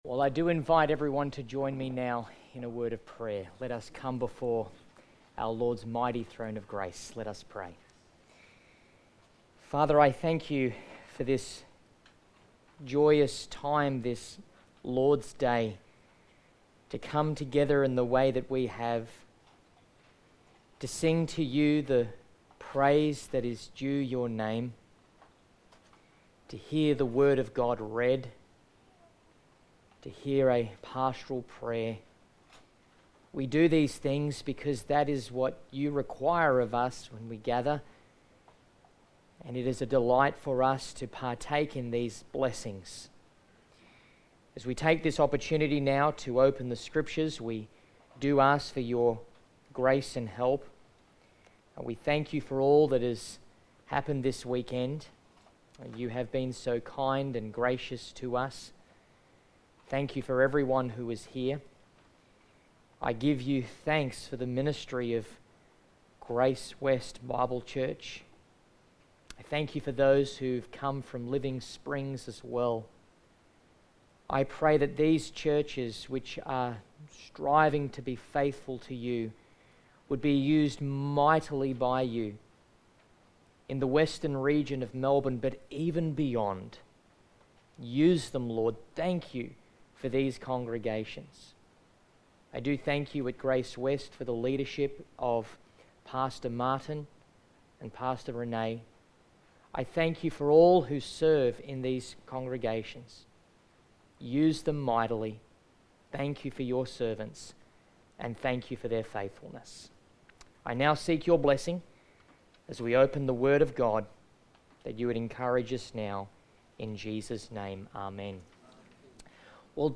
Series: GraceWest Camp 2023
Passage: 1 Peter 3:8-12 Service Type: Special Event